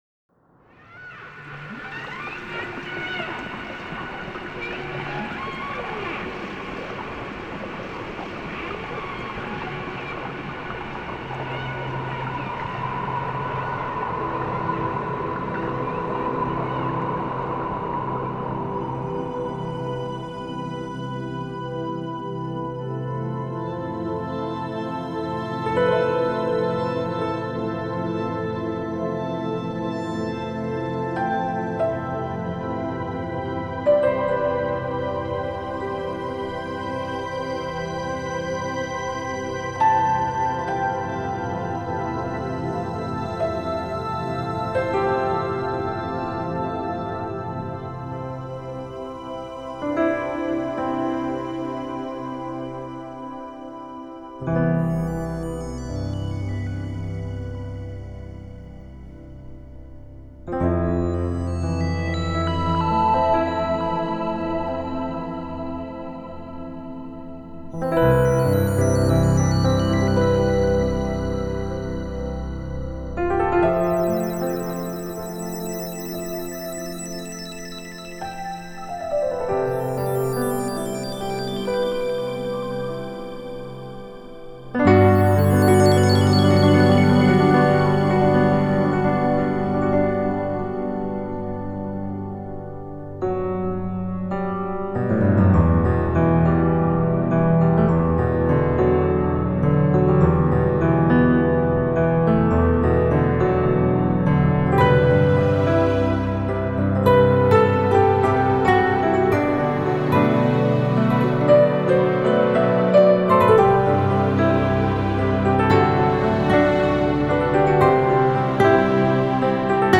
موسیقی بی‌کلام – (چیل‌اوت) تاریخ انتشار
سبک: چیل؛ نیو